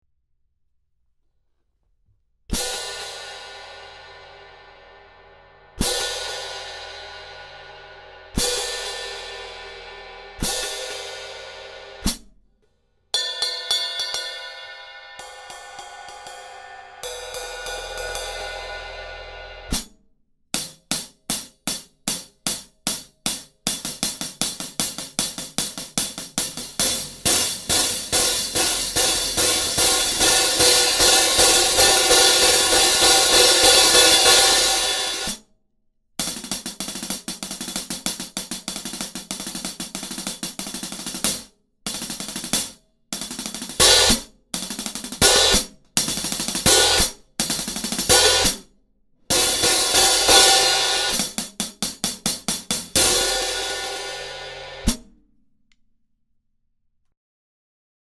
Overtones are reduced and the tone is mellowed making this series popular for recording and for live usage. Less lathing, more raw (unlathed) areas means less resonance and wash, providing a quicker decay than the Classic series.
17″ Studio hi-hat cymbals: Approx 3100 Grams Combined. Top Hat 1415 Grams.Bottom Hat 1685 Grams